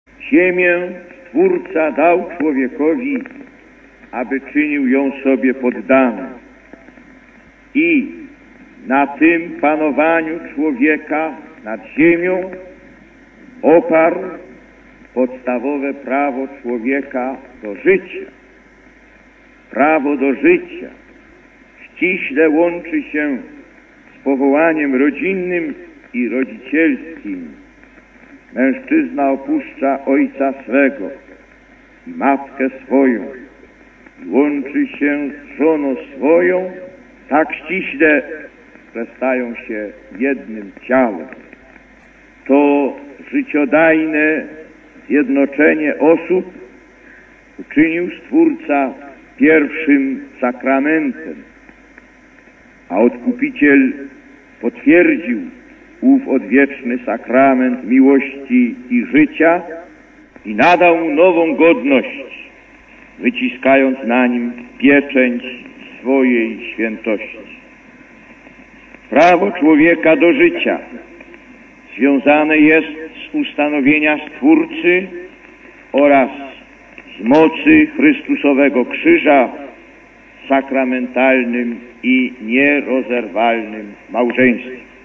Lektor: Z homilii w czasie Mszy św. (Nowy Targ, 8.06.1979 -